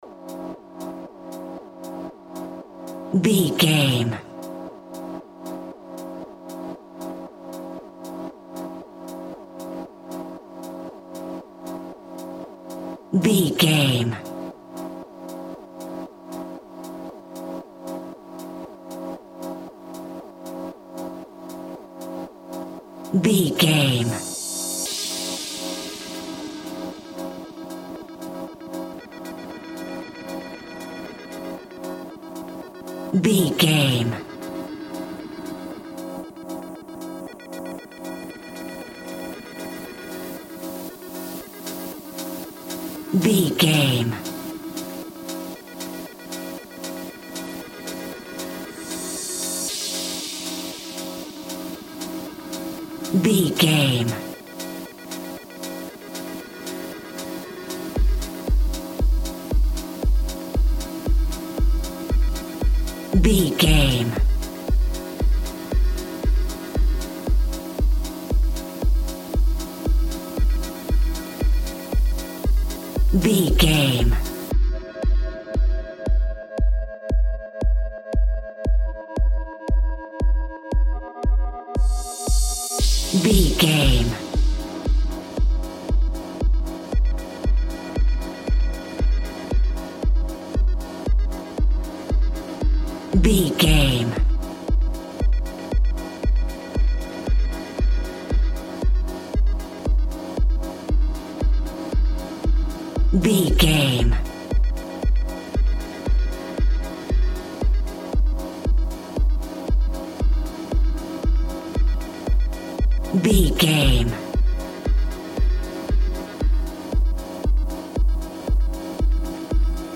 Aeolian/Minor
ethereal
dreamy
cheerful/happy
groovy
synthesiser
drum machine
house
electro dance
techno
trance
synth leads
synth bass
upbeat